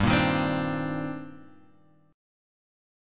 Cm9/G chord {3 1 1 5 3 x} chord